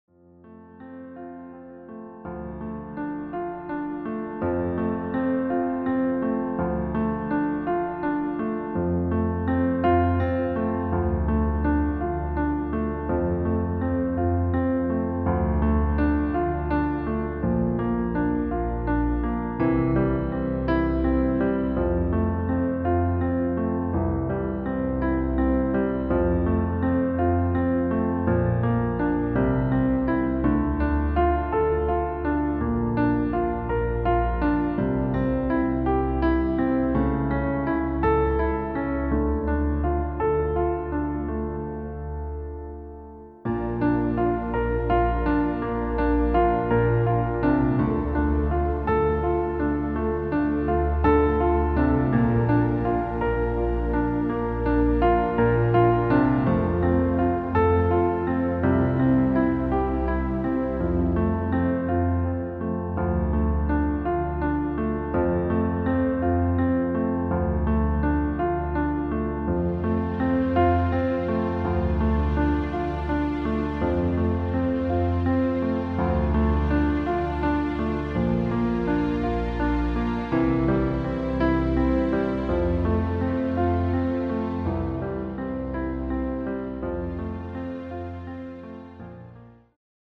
• Tonart: Eb Dur, E-Dur, F-Dur, G-Dur
• Art: Klavierversion mit Streicher
• Das Instrumental beinhaltet keine Leadstimme
Lediglich die Demos sind mit einem Fade-In/Out versehen.
Klavier / Streicher